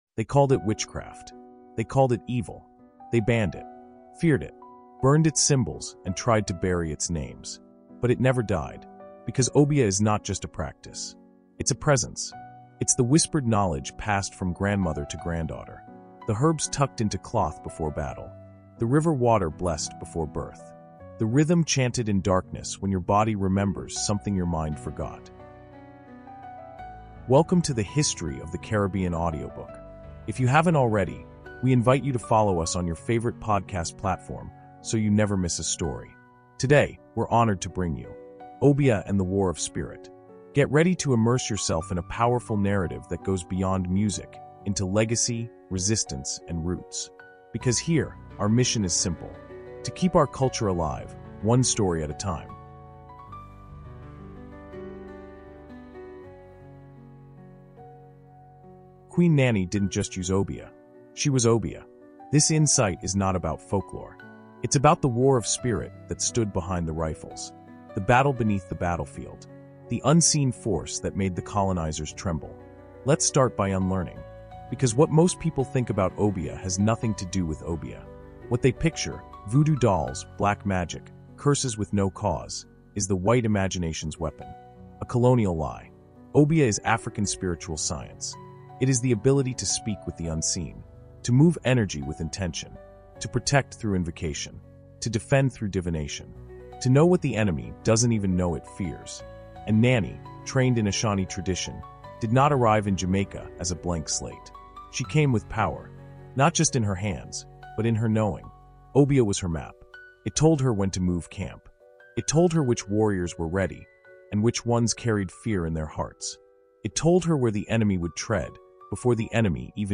In this haunting and powerful 20-minute audio insight, we uncover the sacred weaponry Queen Nanny carried beyond blade and drum—her mastery of Obeah, the African spiritual science born of resistance and remembrance. Through visions, ritual, and divine knowledge, Nanny didn’t just lead her people—she shielded them.